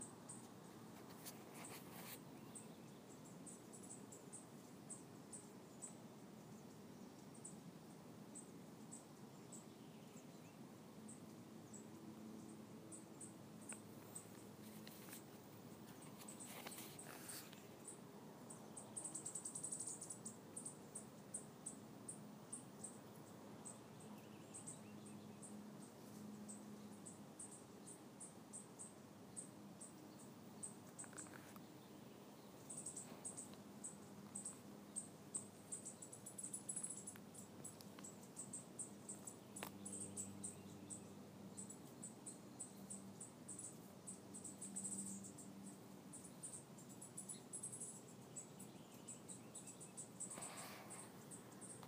WIWA begging